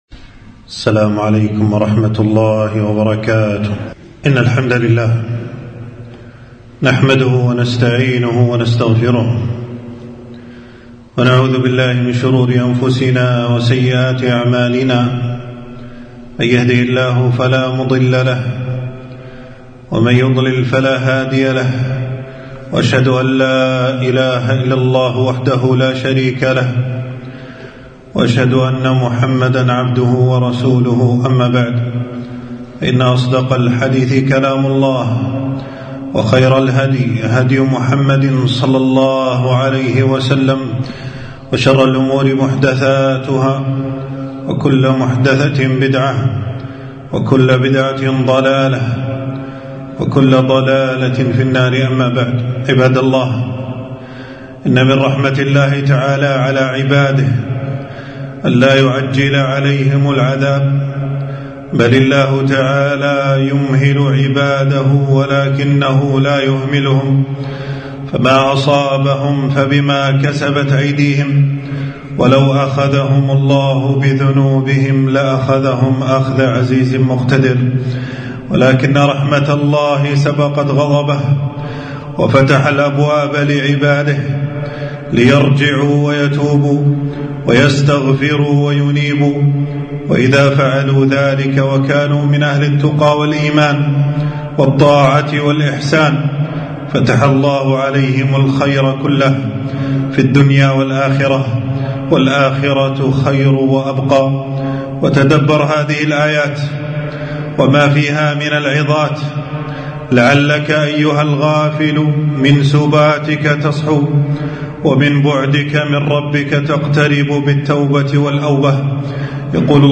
خطبة - لا ملجأ لنا إلا إلى الله، فتوبوا إليه وتوكلوا عليه